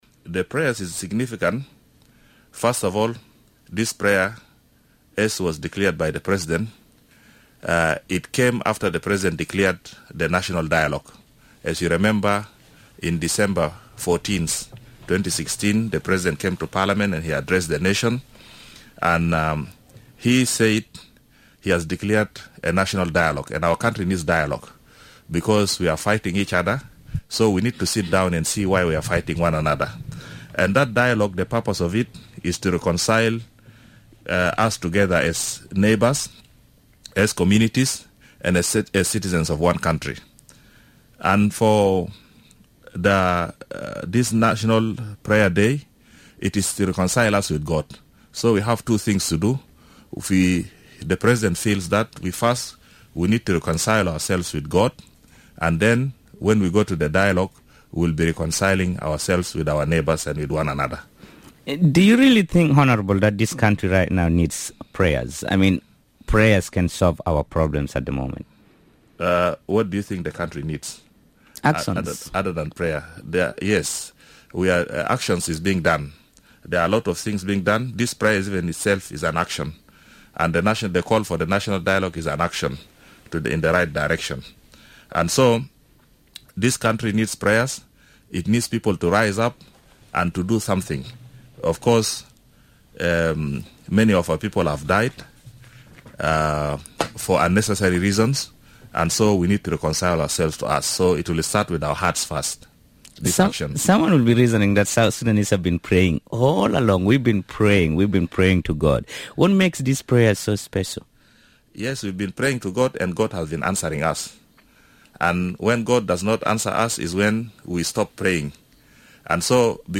Here is Anthony Lino Makana, the speaker of the Transitional National Legislative Assembly.